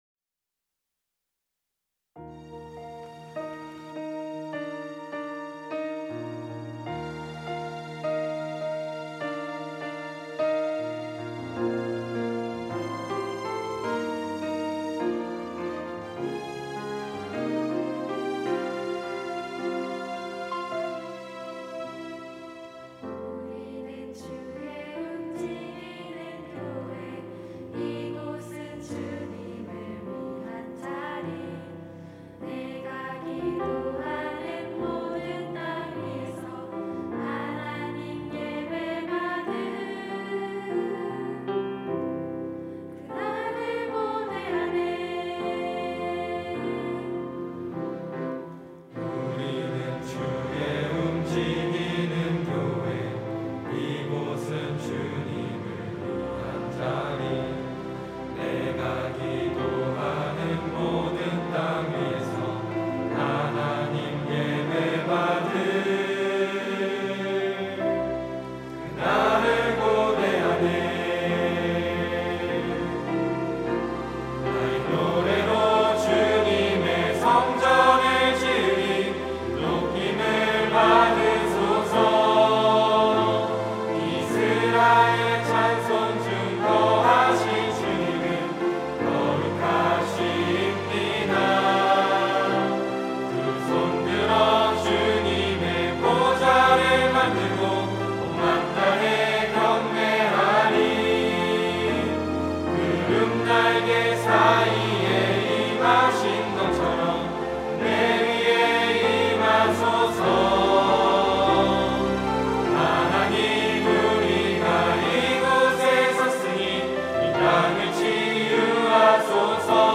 특송과 특주 - 우리는 주의 움직이는 교회
청년부 새가족 수료자